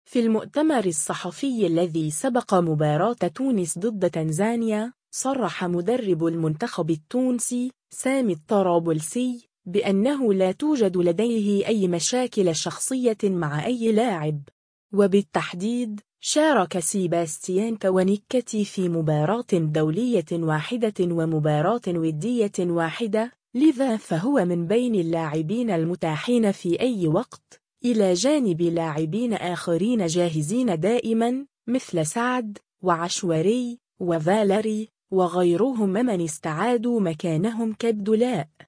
في المؤتمر الصحفي الذي سبق مباراة تونس ضد تنزانيا